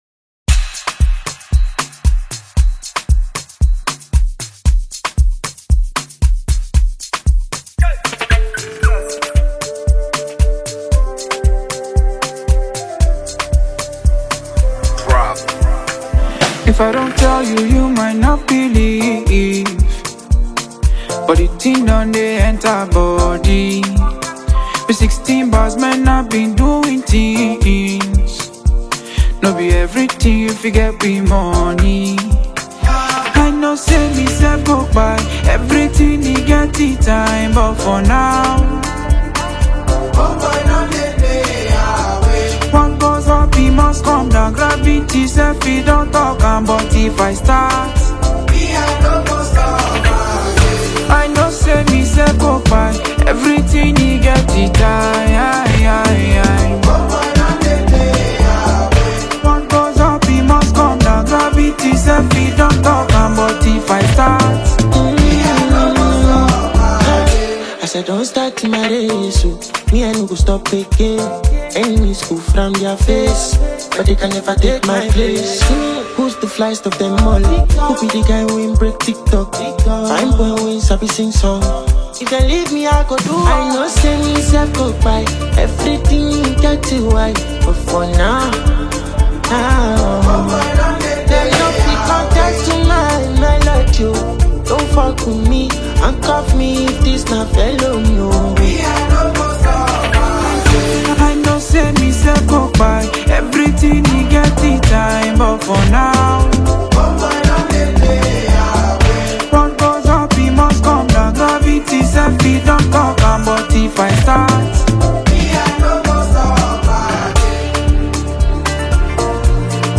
a shocking vocalist.